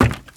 High Quality Footsteps
Wood, Creaky
STEPS Wood, Creaky, Run 21.wav